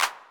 snare43.ogg